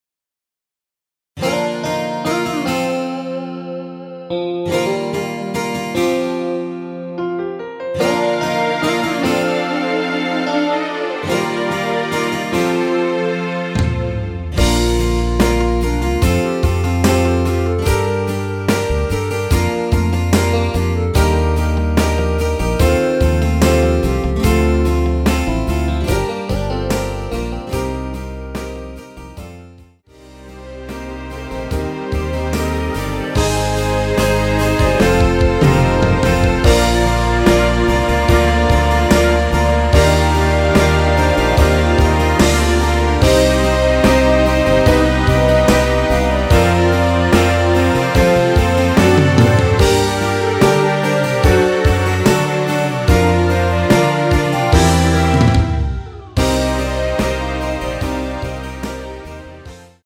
원키에서(-9)내린 멜로디 포함된 MR입니다.
Bb
앞부분30초, 뒷부분30초씩 편집해서 올려 드리고 있습니다.
중간에 음이 끈어지고 다시 나오는 이유는